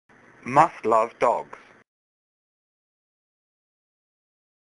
來！讓小丸子陪你邊聊明星，邊練發音，當個真正的追星族！